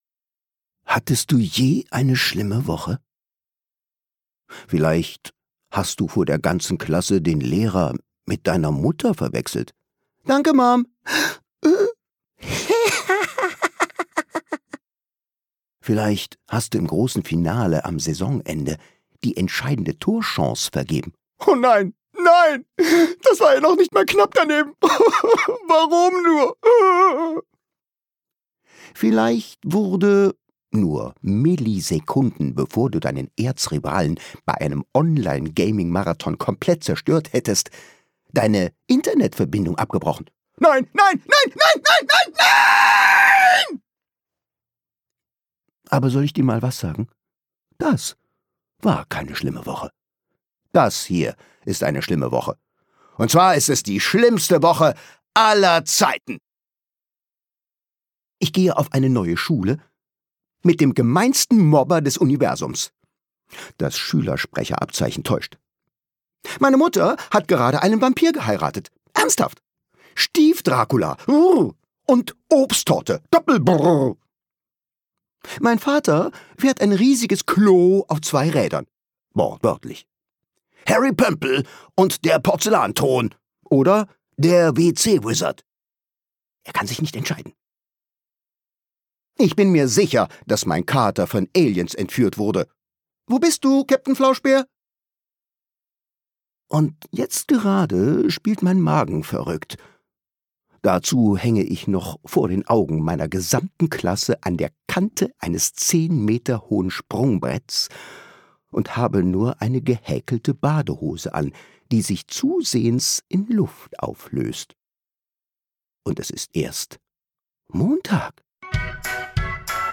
Schule, Peinlichkeiten und ganz viel Humor – Cooles Hörbuch für Kinder ab 10 Jahre
Worst Week Ever – Montag Gelesen von: Thomas Nicolai